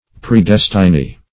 Predestiny \Pre*des"ti*ny\, n.